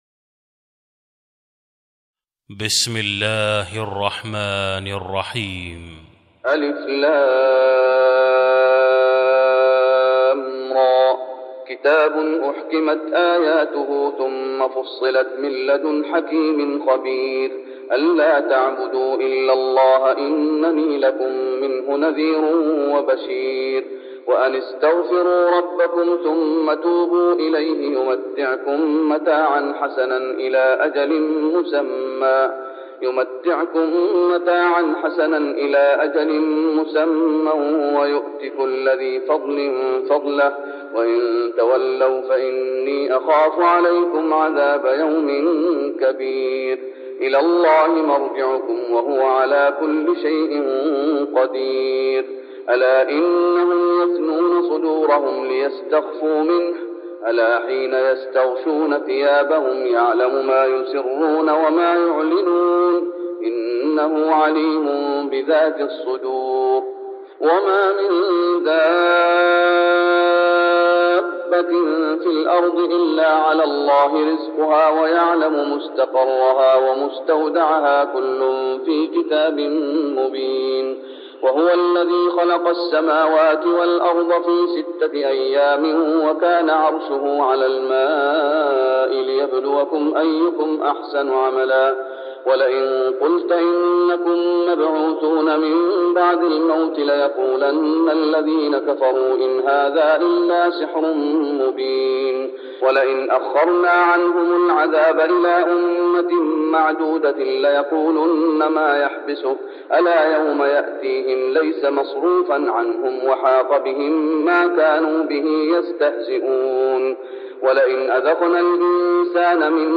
تهجد رمضان 1410هـ من سورة هود (1-95) Tahajjud Ramadan 1410H from Surah Hud > تراويح الشيخ محمد أيوب بالنبوي عام 1410 🕌 > التراويح - تلاوات الحرمين